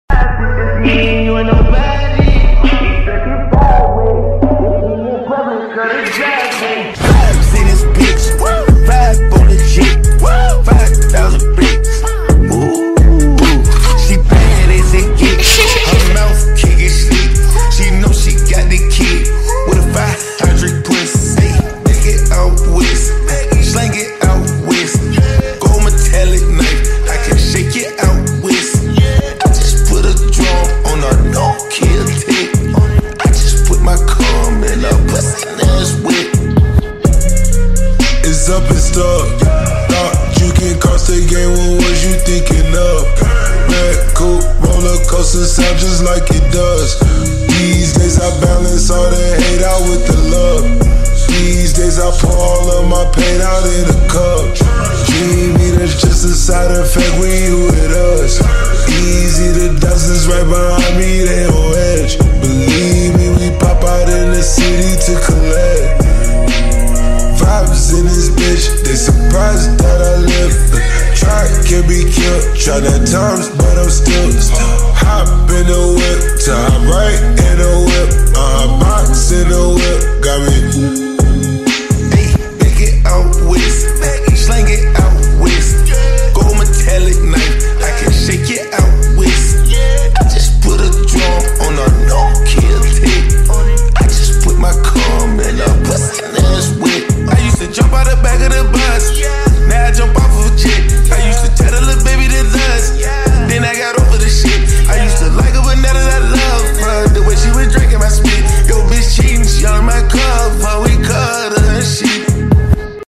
ژانر: رپ/هیپ پاپ